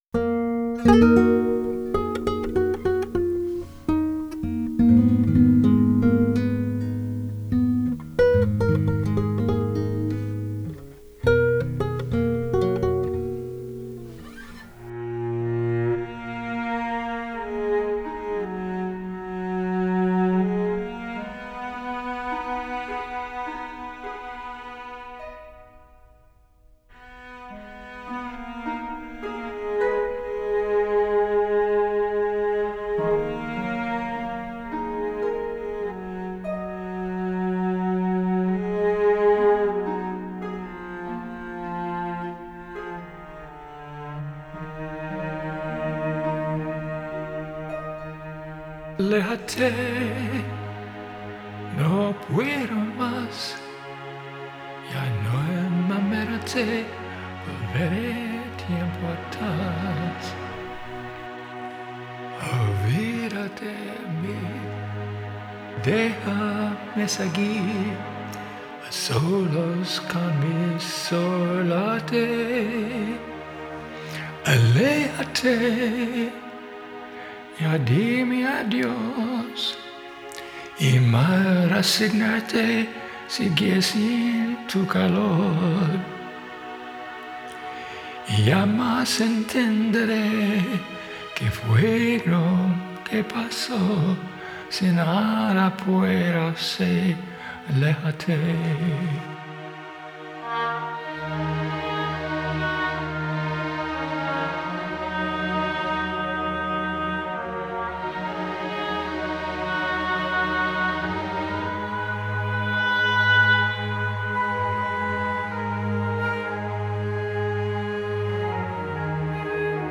Piano, Guitar, Drums, Vocals
Drums and Bass
Saxophone
Trumpet